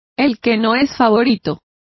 Complete with pronunciation of the translation of underdogs.